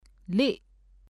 ထီး [tʰí ]